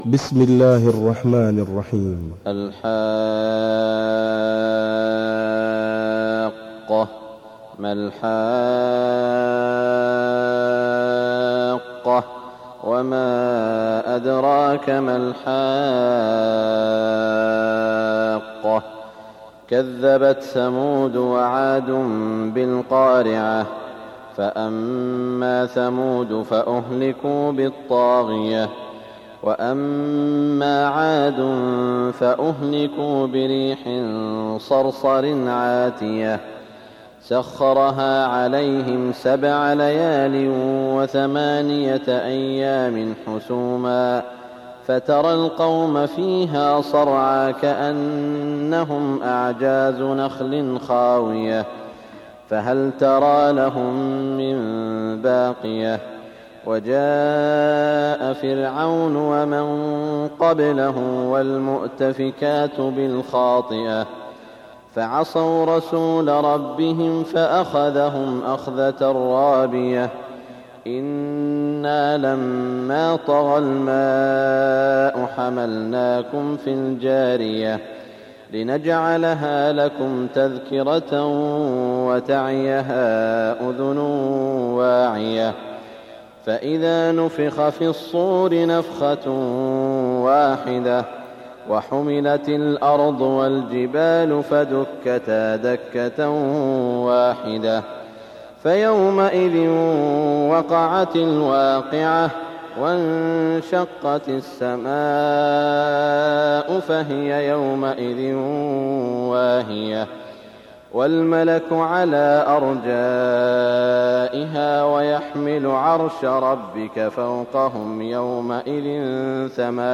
صلاة الفجر 1414هـ من سورة الحاقة > 1414 🕋 > الفروض - تلاوات الحرمين